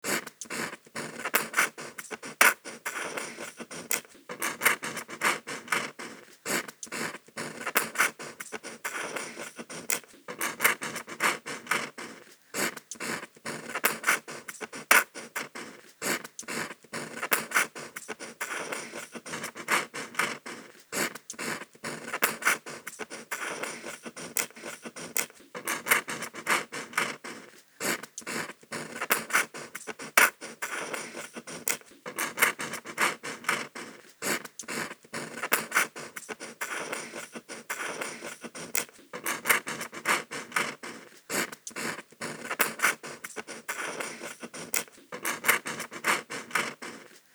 Звуки писания пером
Шум пера в каллиграфии